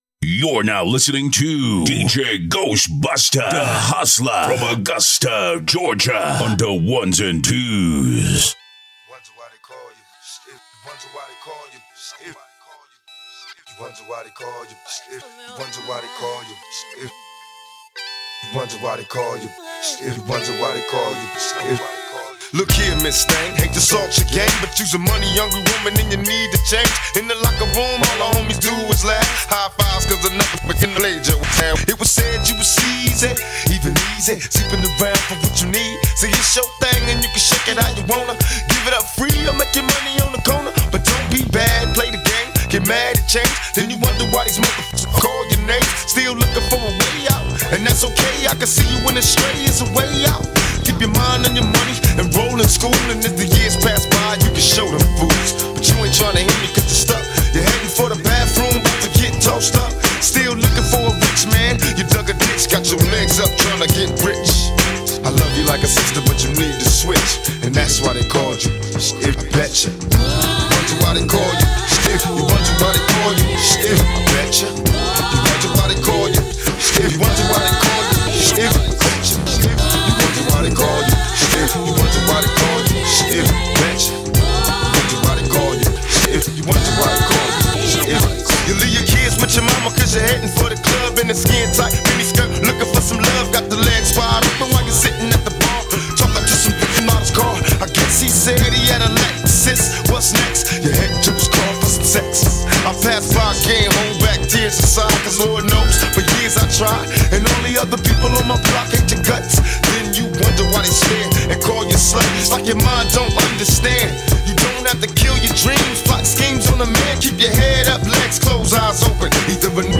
Hiphop
Quick Mix